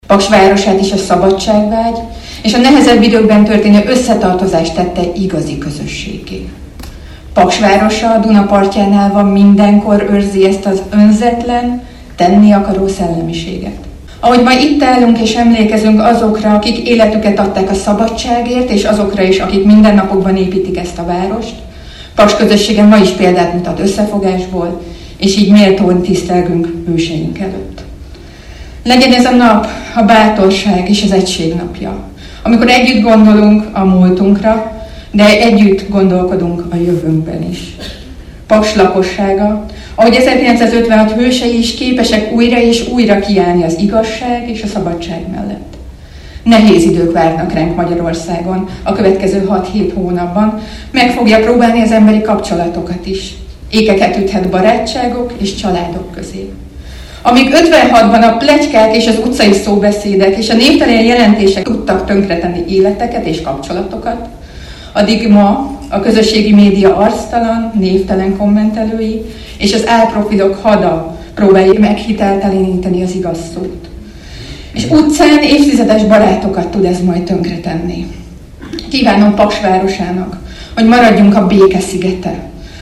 Este a Csengey Dénes Kulturális Központban folytatódott az ünnepség, melyen köszöntőt mondott Heringes Anita, Paks város polgármestere, ebből hallhatnak részletet.